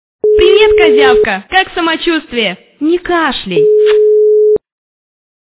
» Звуки » Смешные » Женский голос - Привет, Казявка. Как самочуствие? Не кашляй.
При прослушивании Женский голос - Привет, Казявка. Как самочуствие? Не кашляй. качество понижено и присутствуют гудки.
Звук Женский голос - Привет, Казявка. Как самочуствие? Не кашляй.